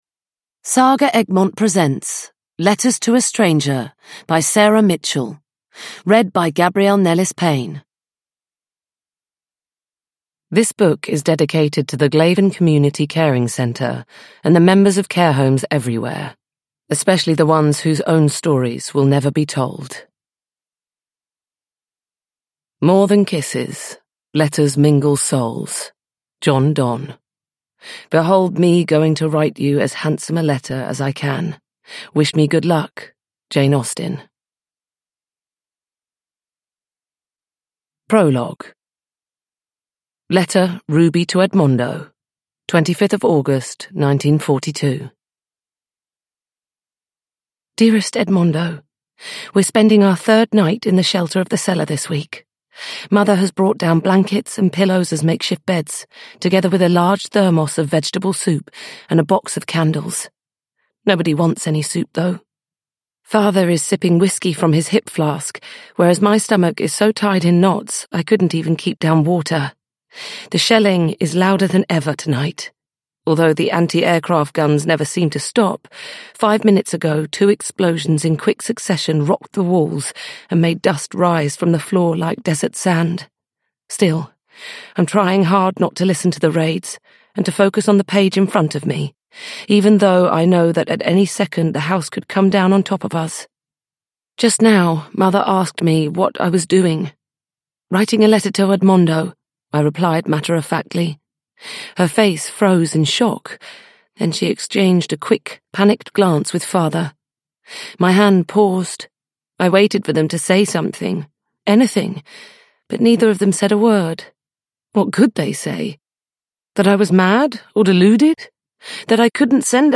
Downloadable Audiobook